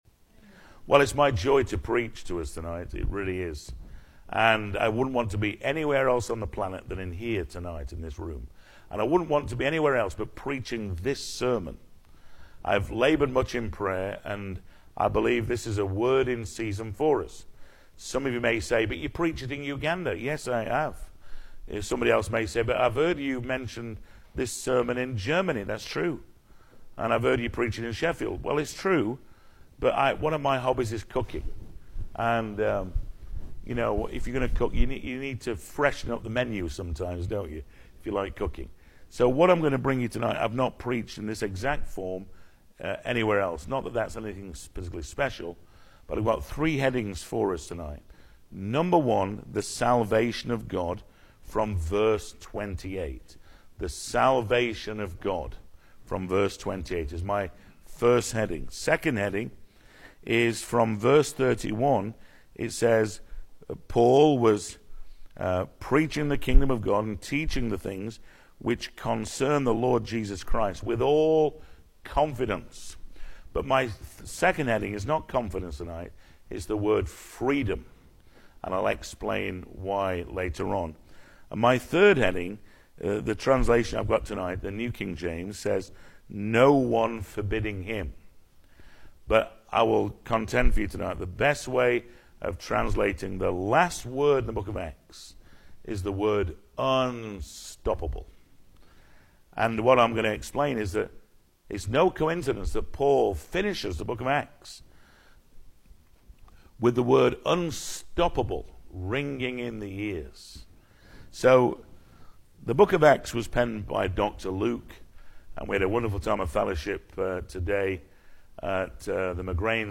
2024 Service Type: Sunday Evening Speaker